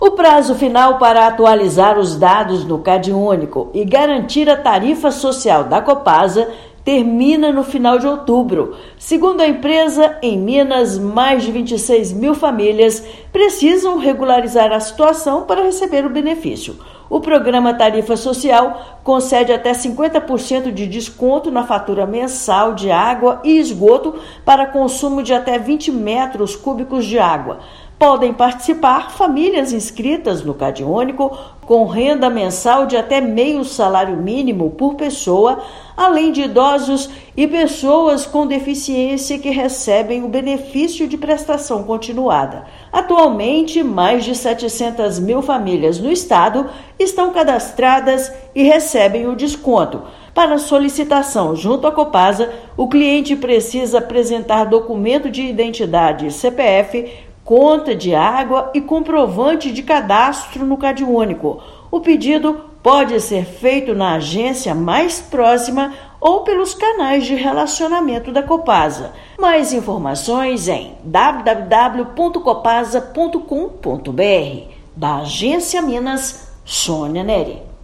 Em todo o estado, ao menos 26 mil famílias têm direito e devem atualizar o benefício até o final de outubro. Ouça matéria de rádio.